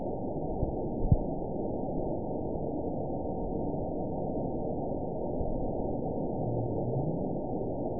event 922715 date 03/17/25 time 23:16:08 GMT (1 month, 2 weeks ago) score 8.29 location TSS-AB04 detected by nrw target species NRW annotations +NRW Spectrogram: Frequency (kHz) vs. Time (s) audio not available .wav